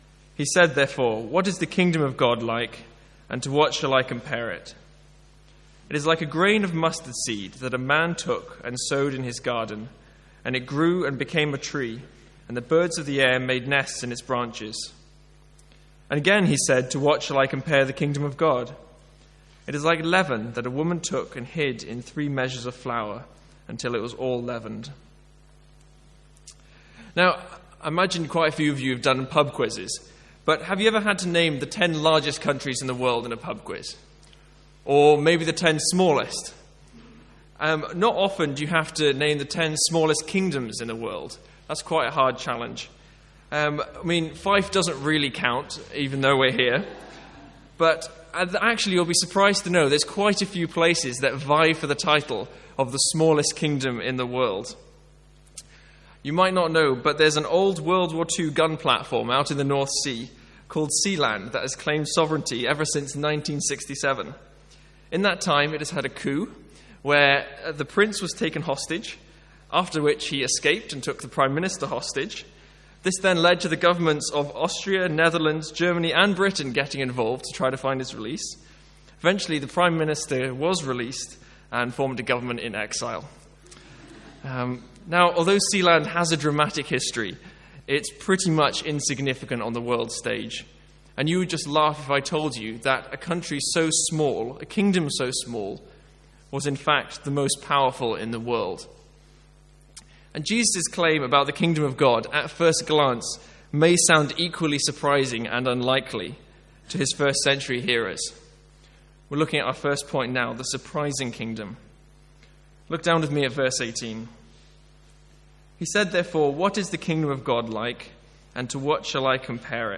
Sermons | St Andrews Free Church
From the evening series in Luke.